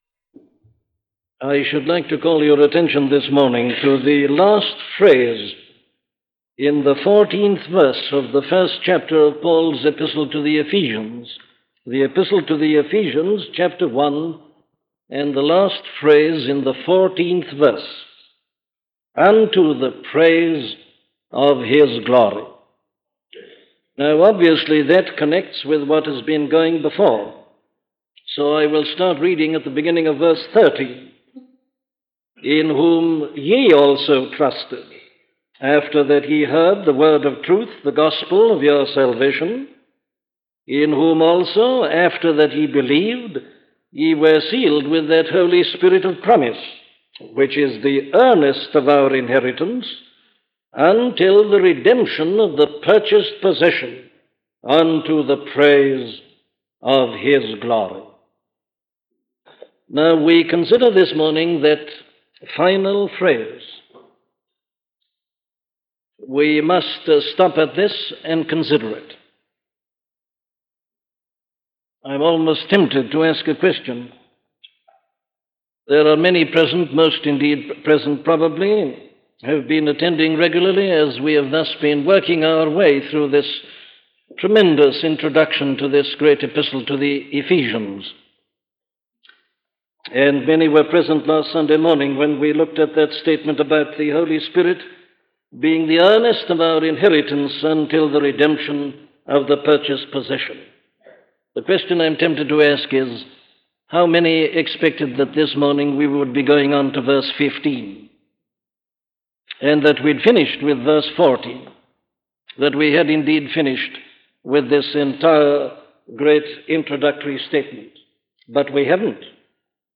To the Praise of His Glory - a sermon from Dr. Martyn Lloyd Jones
Listen to the sermon on Ephesians 1:14 'To the Praise of His Glory' by Dr. Martyn Lloyd-Jones
Scripture